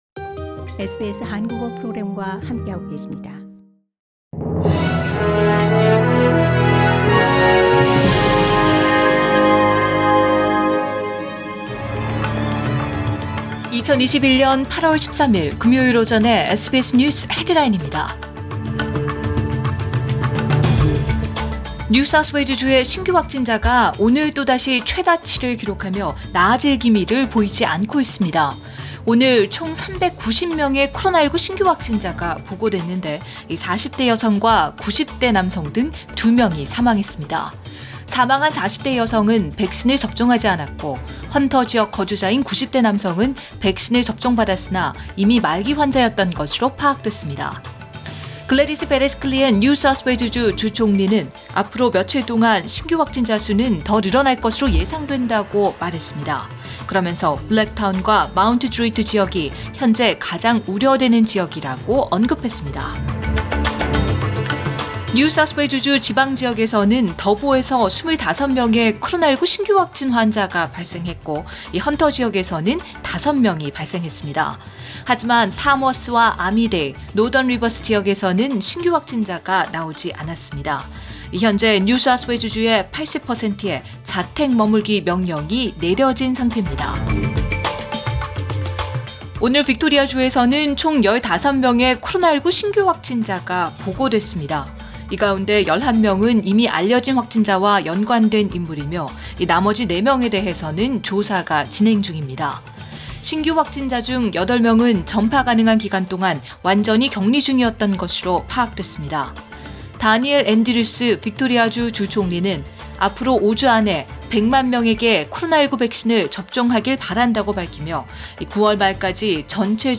2021년 8월 13일 금요일 오전의 SBS 뉴스 헤드라인입니다.